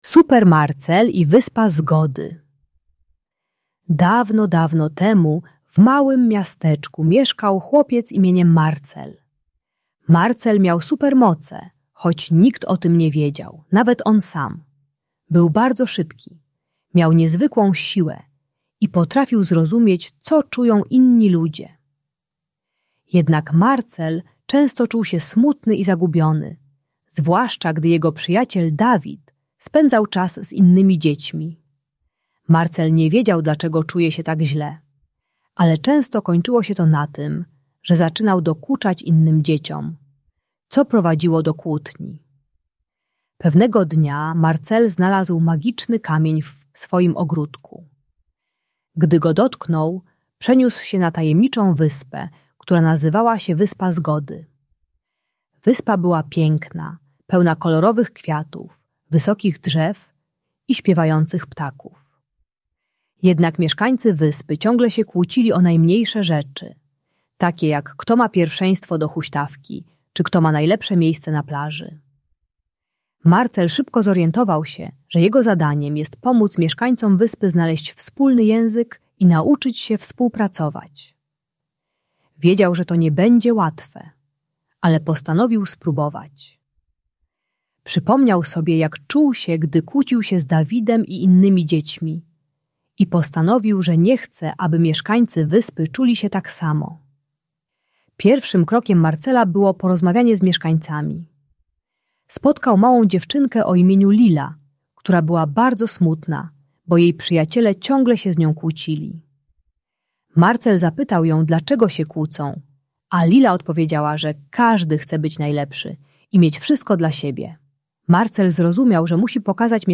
Super Marcel i Wyspa Zgody - Szkoła | Audiobajka